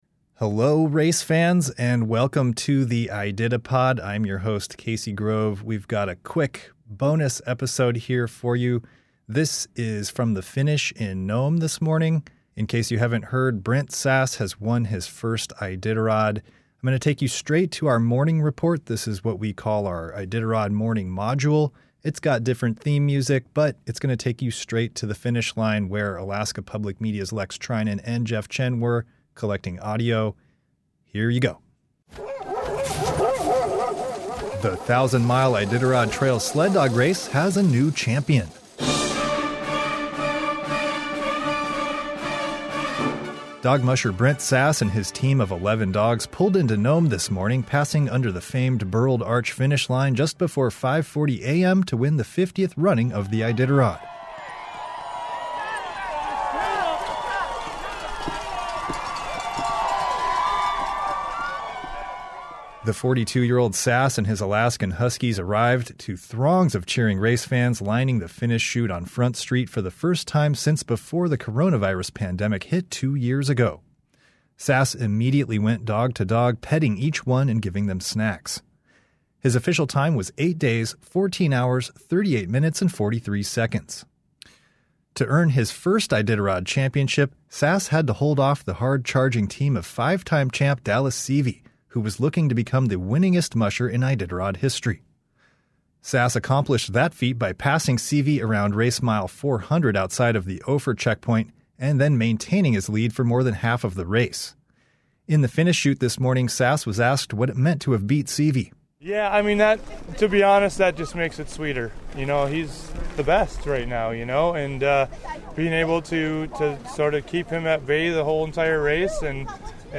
The 1,000-mile Iditarod Trail Sled Dog Race has a new champion, as you'll hear in this morning Iditarod report from Alaska Public Media.